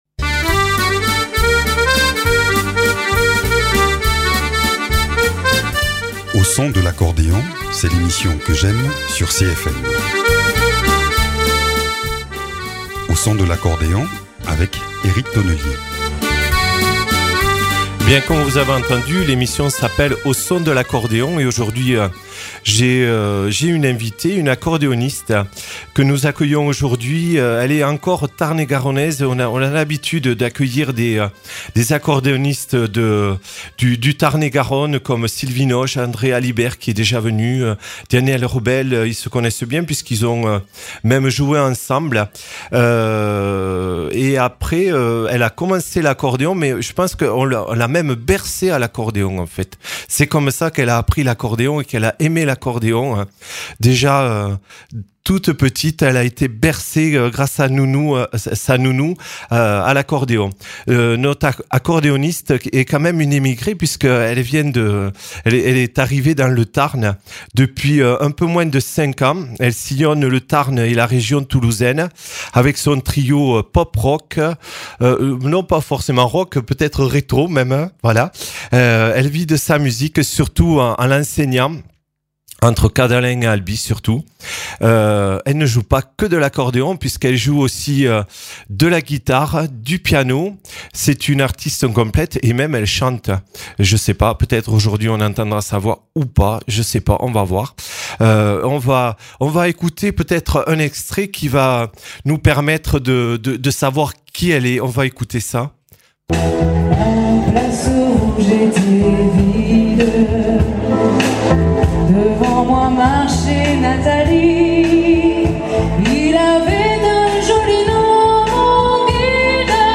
Aujourd’hui en studio avec son accordéon elle nous joue quelques morceaux